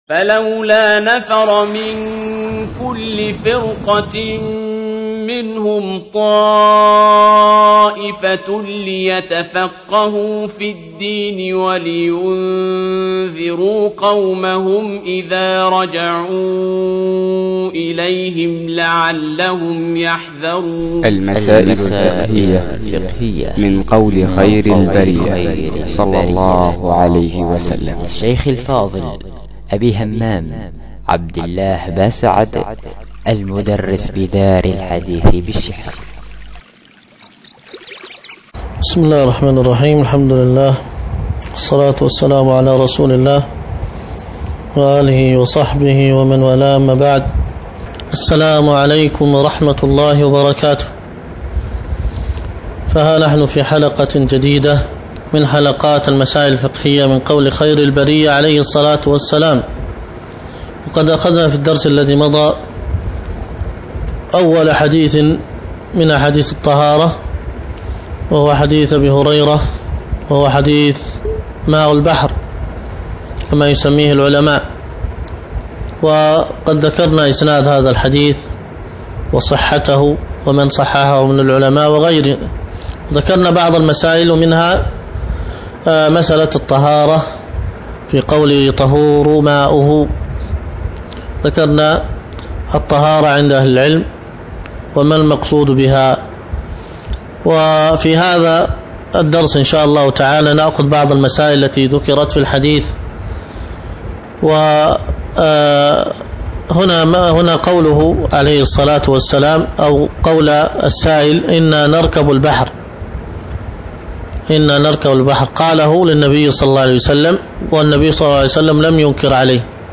المسائل الفقهية من قول خير البرية 17 | المسائل الفقهية من قول خير البرية - برنامج إذاعي ( مكتمل )